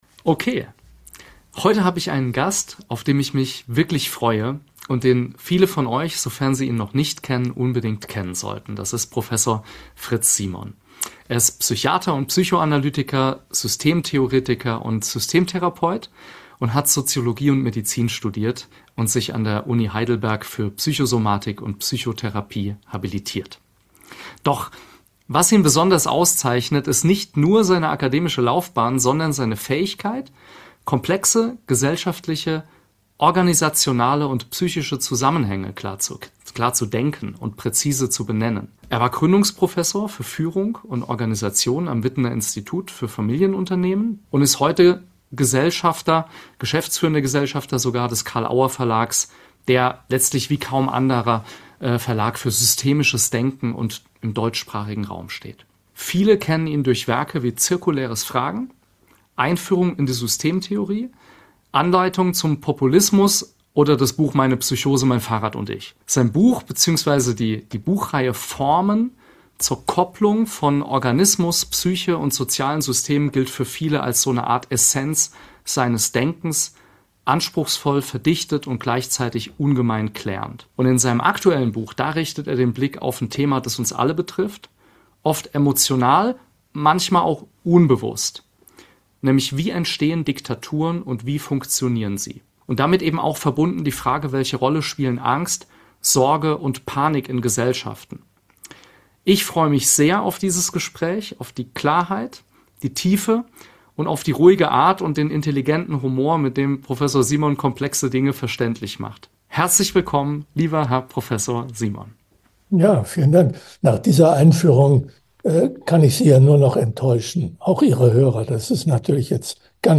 Ein Dialog über Macht und Ohnmacht.